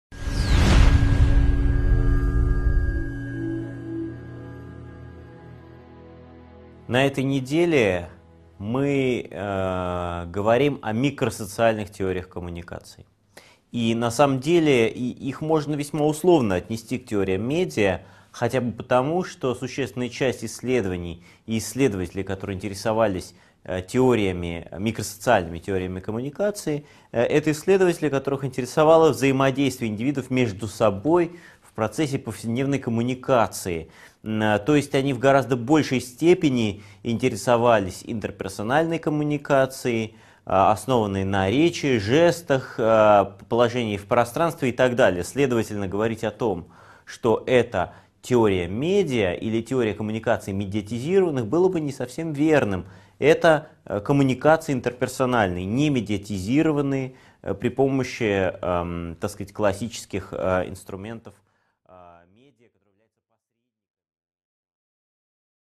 Аудиокнига 10.1 Микросоциальные теории медиа: Школа Пало Альто | Библиотека аудиокниг